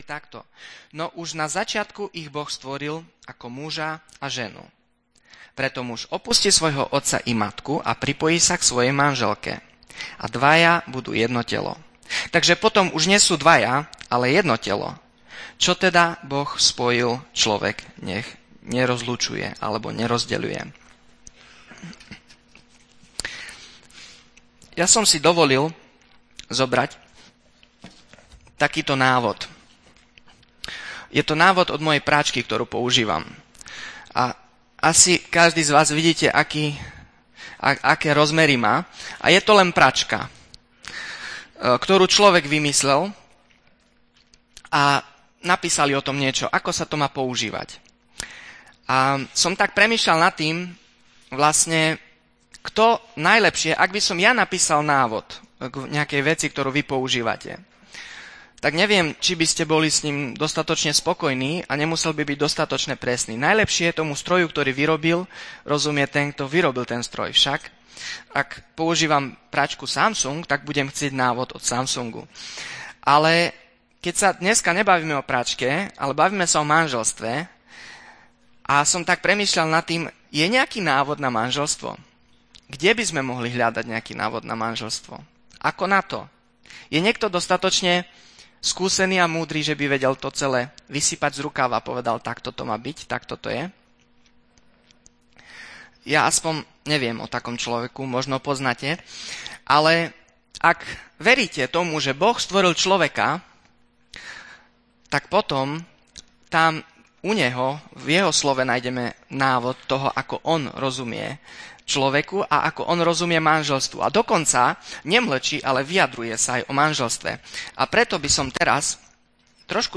Sobášna kázeň